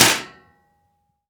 metal_impact_light_04.wav